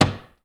BUTTON_Medium_Click_mono.wav